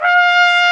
Index of /90_sSampleCDs/Roland L-CDX-03 Disk 2/BRS_Tpt mf menu/BRS_Tp mf menu
BRS W.TRPT1H.wav